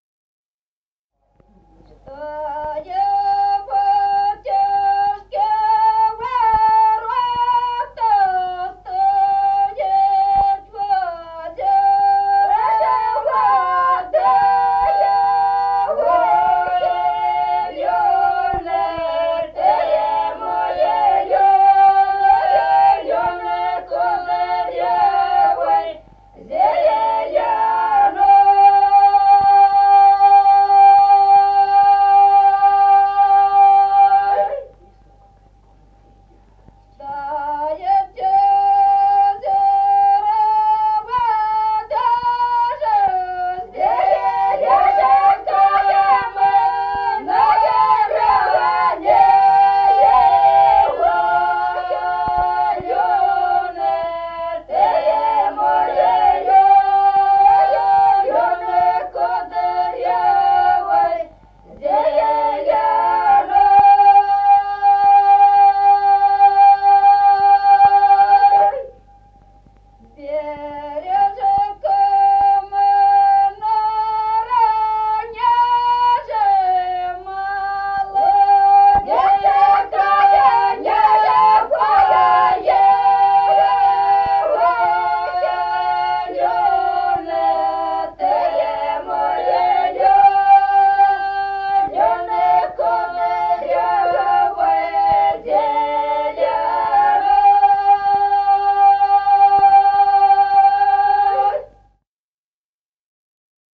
Народные песни Стародубского района «Что у батюшки ворот», карагодная.
1953 г., с. Остроглядово.